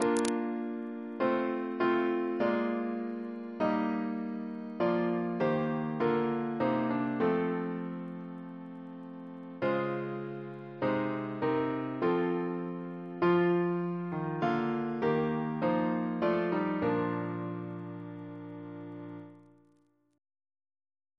Double chant in B♭ Composer: Walter Biery (b.1958)